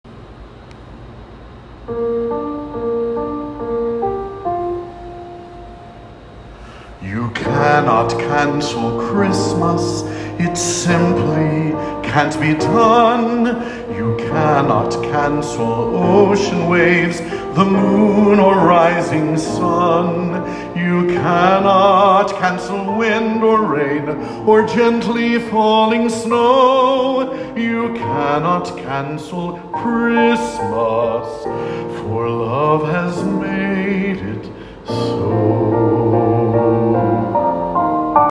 You Cannot Cancel Christmas – Vocal DemoDownload